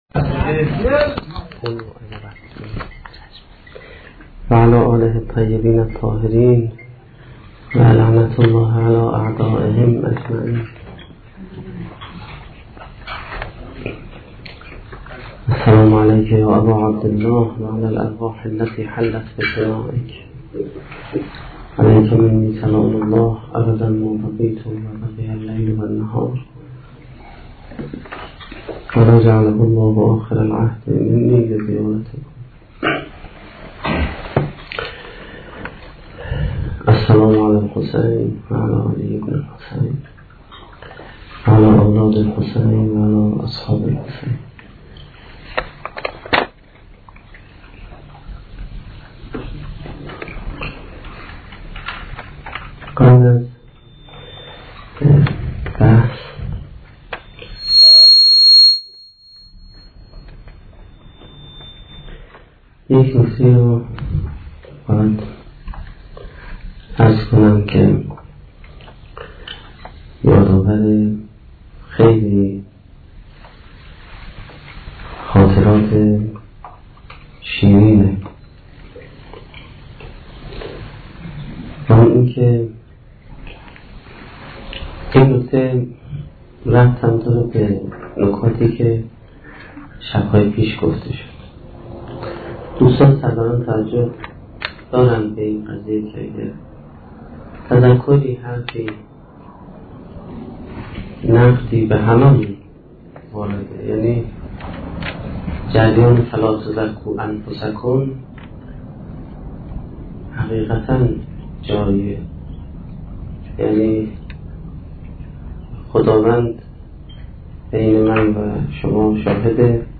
سخنرانی سومین شب دهه محرم1435-1392